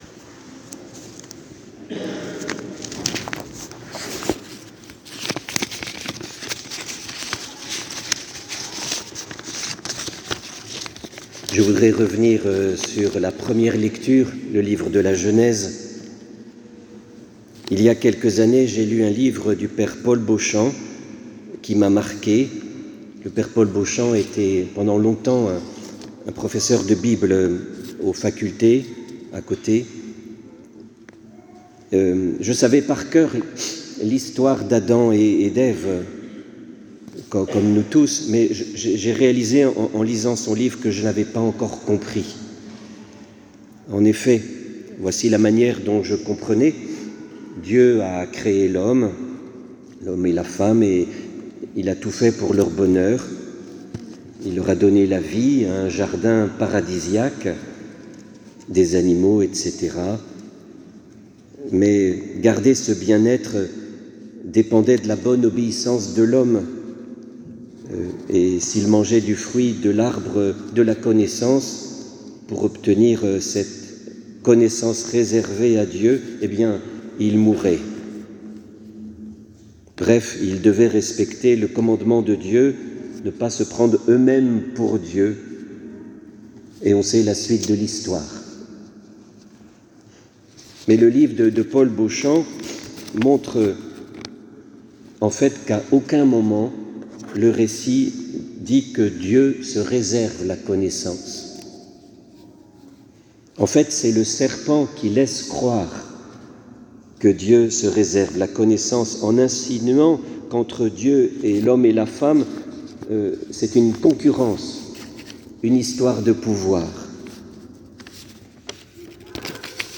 Homélie sur Gn 2,7..3,7 et Mt 4,1-11
Homelie-le-_manger-_et-le-_connaitre_.mp3